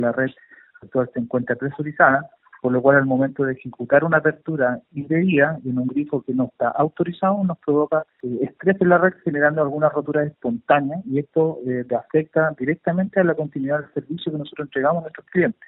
En conversación con La Radio